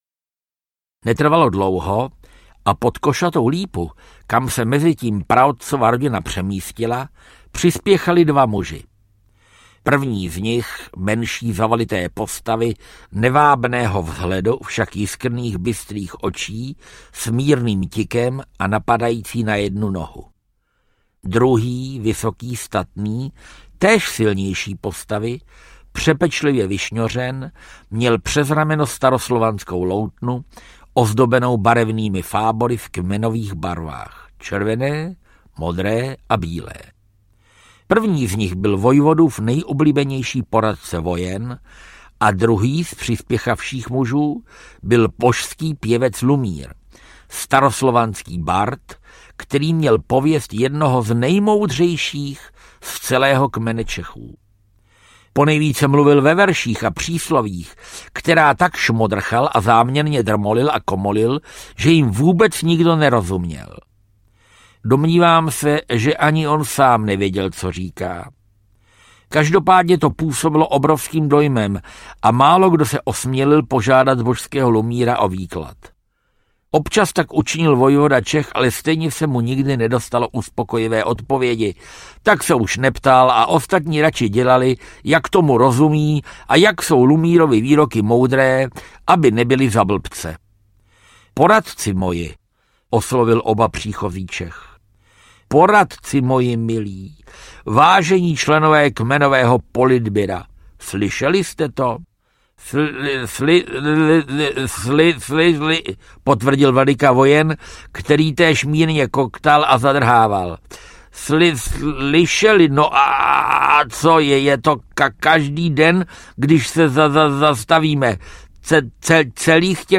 Pra pra pra audiokniha
Ukázka z knihy
• InterpretFrantišek Ringo Čech, Uršula Kluková